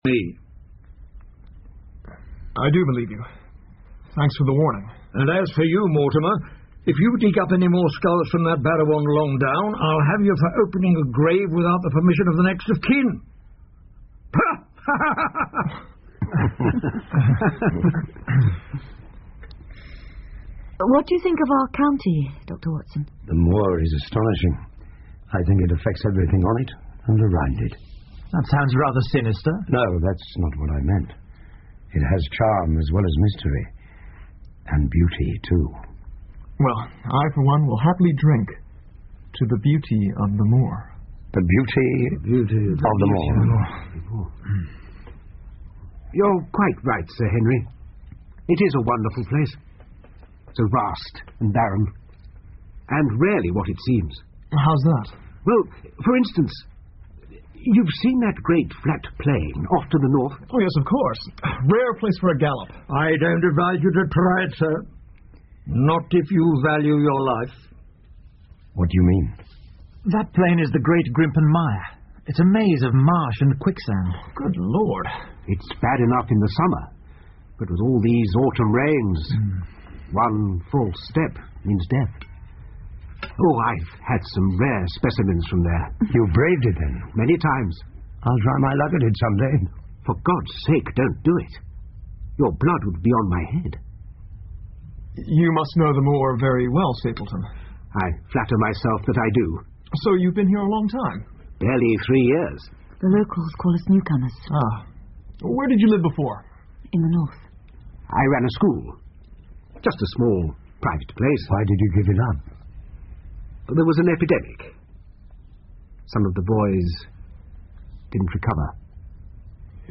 福尔摩斯广播剧 The Hound Of The Baskervilles - Part 01-10 听力文件下载—在线英语听力室